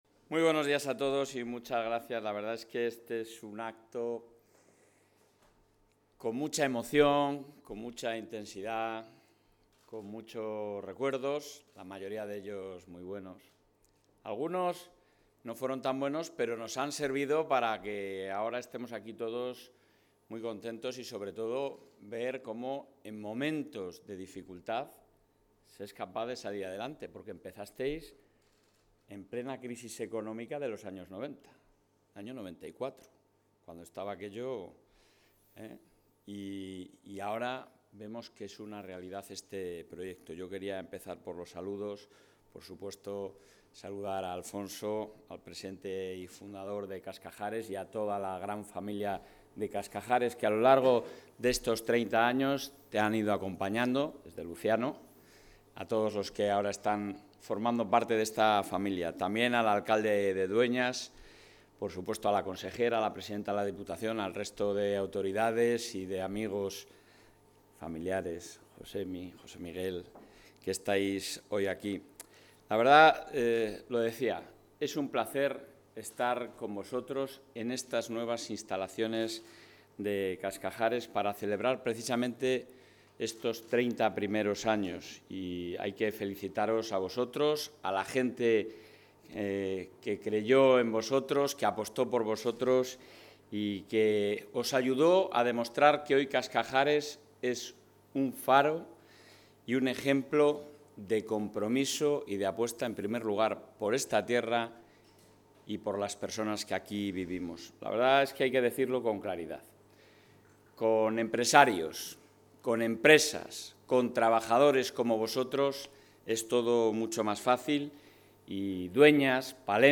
Intervención del presidente de la Junta.
El presidente de la Junta de Castilla y León, Alfonso Fernández Mañueco, ha visitado hoy las nuevas instalaciones de la planta de Cascajares en la localidad palentina de Dueñas, con motivo de la conmemoración del 30º aniversario de esta empresa de alimentos de alta calidad.